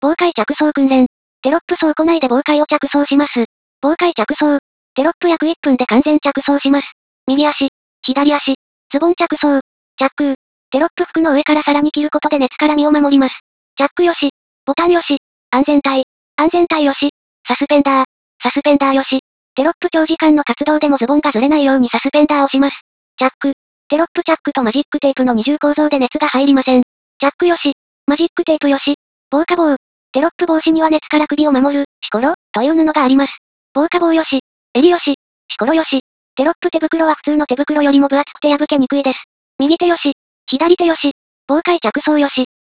音声解説（ダウンロード）